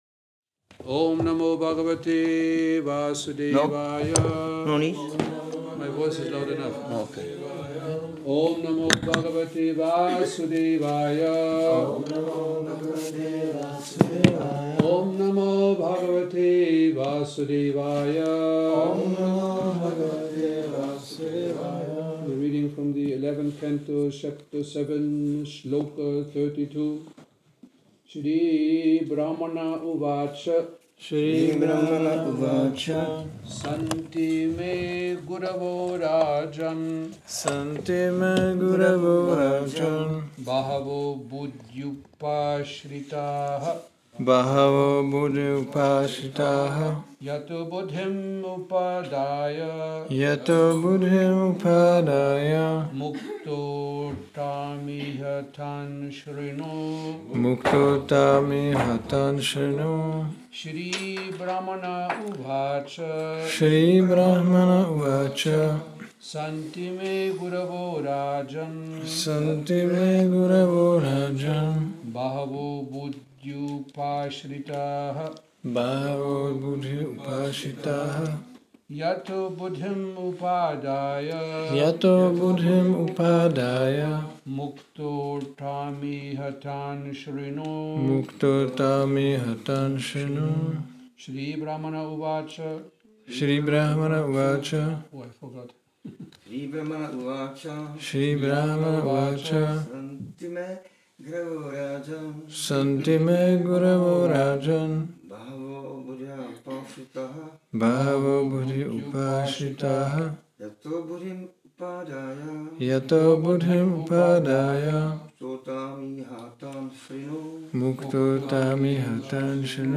Přednáška SB-11.7.32 – Šrí Šrí Nitái Navadvípačandra mandir